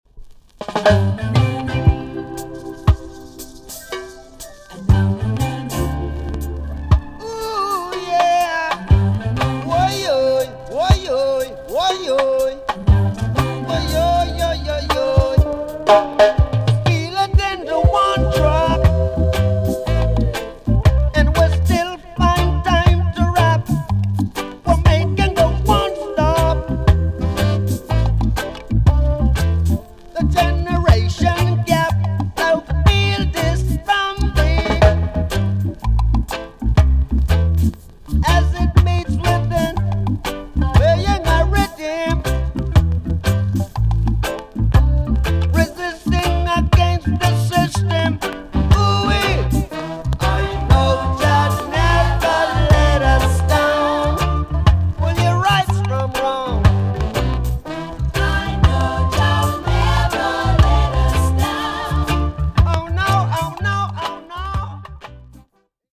A.SIDE EX-~VG+ 少しプチノイズの箇所がありますが音は良好です。